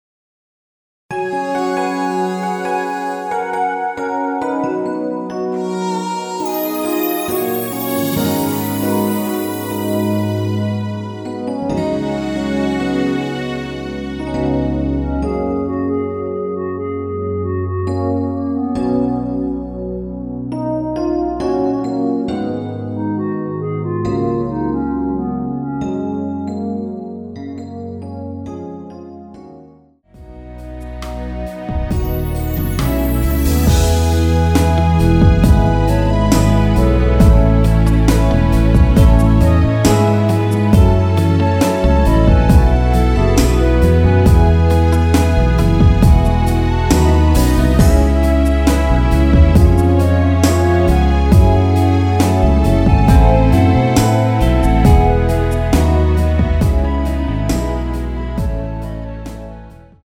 원키에서(-1) 내린 멜로디 포함된 MR입니다.
F#
앞부분30초, 뒷부분30초씩 편집해서 올려 드리고 있습니다.